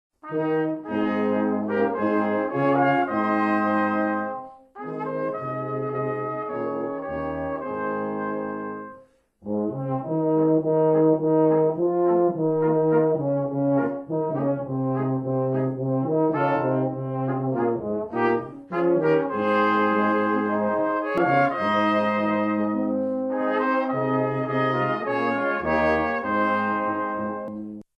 Einstimmiger Chorgesang zu 86 deutschen Volksliedern.
Probenmitschnitt